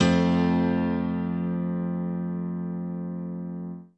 BAL Piano Chord F.wav